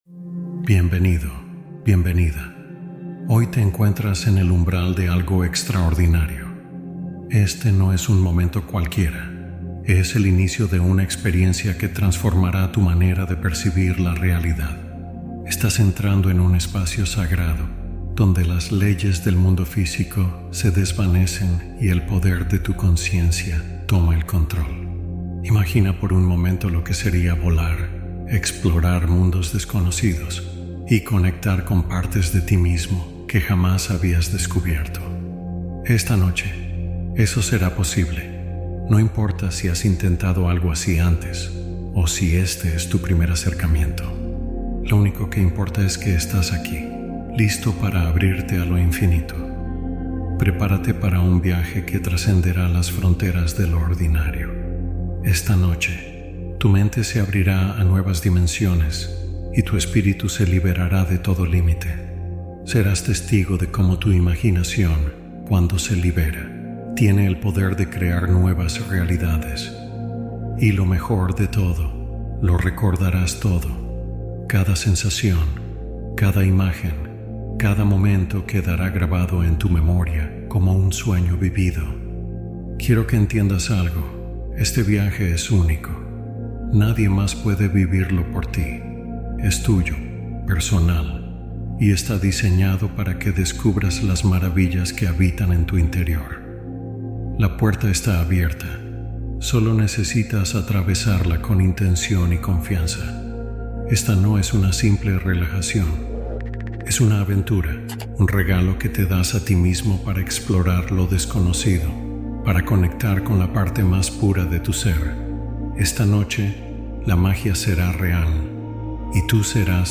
Meditación Para Vivir una Experiencia de Viaje Astral